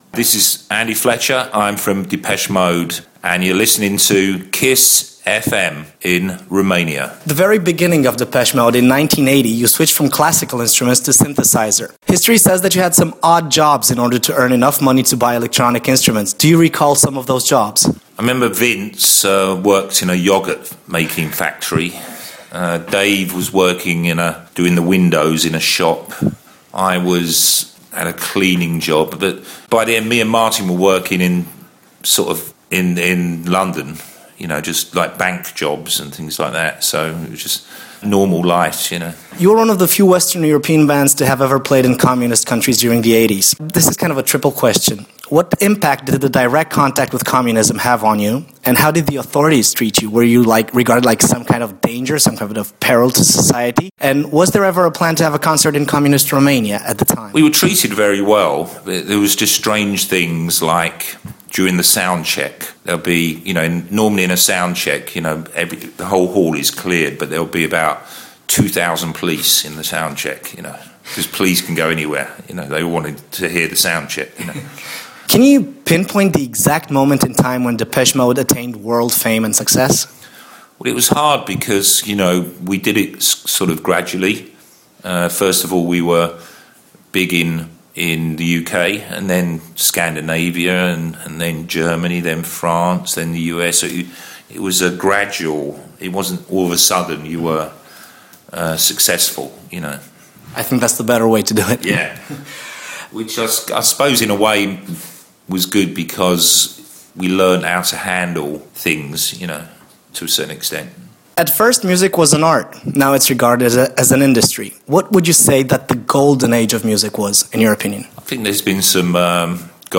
Interviurile Kiss FM